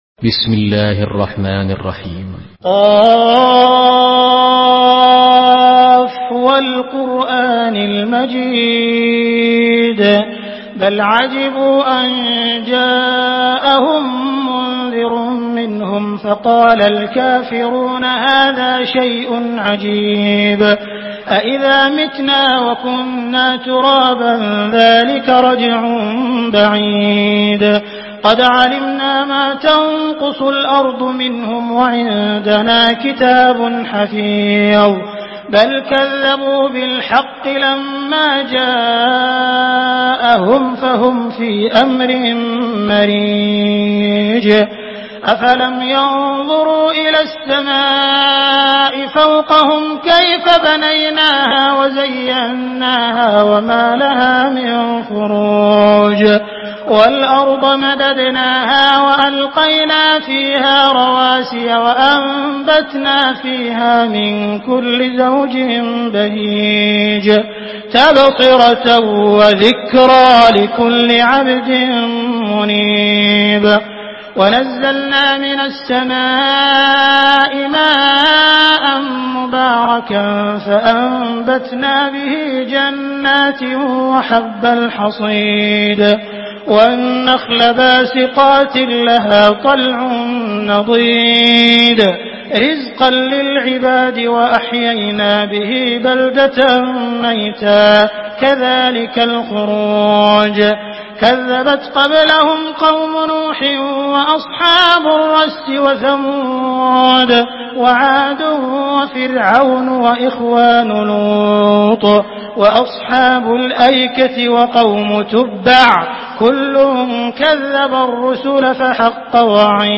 Surah Qaf MP3 by Abdul Rahman Al Sudais in Hafs An Asim narration.
Murattal